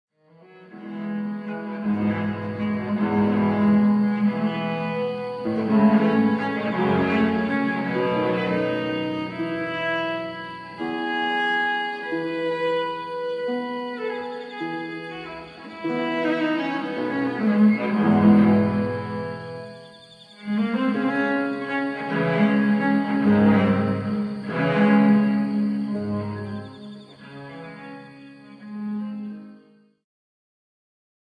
ジャンル Progressive
リュート
管楽器フューチュア
弦楽器系
古楽
癒し系
中世・ルネサンス・初期バロックの曲をブロークンコンソート形式で演奏。